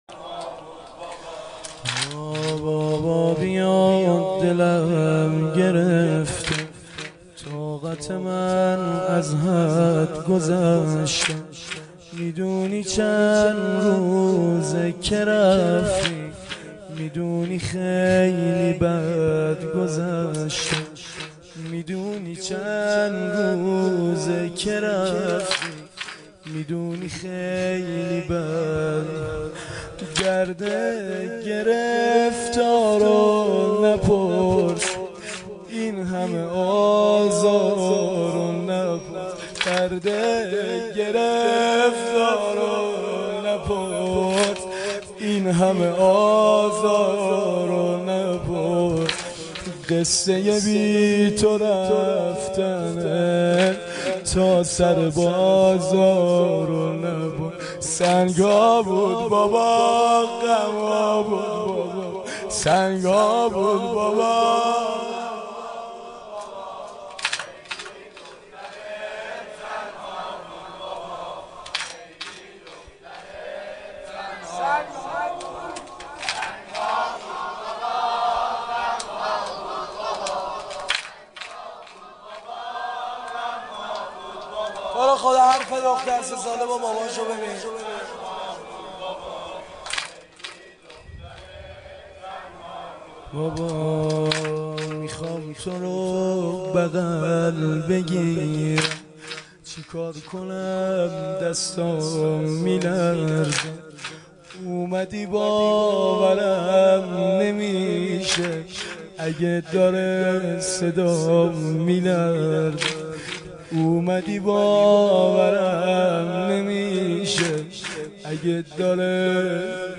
شب سوم محرم ۱۴۴۱